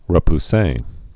(rə-p-sā)